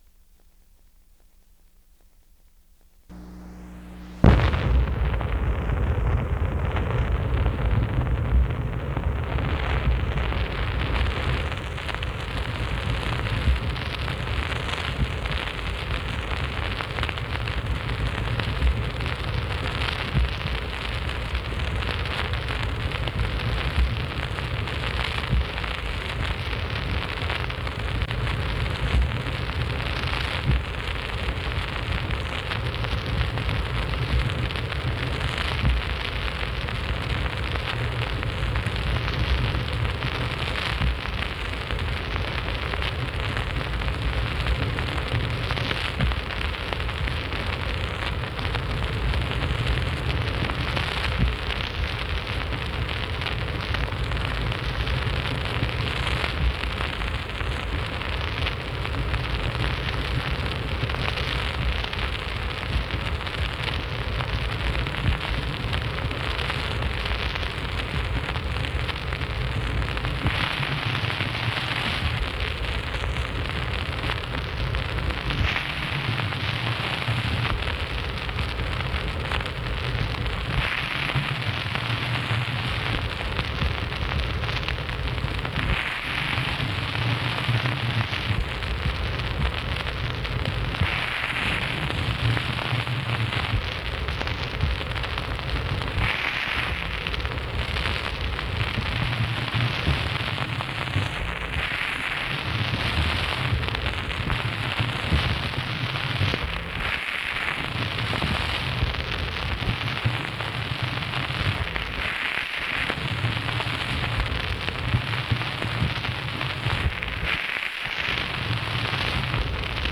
Secret White House Tapes | Harry S. Truman Presidency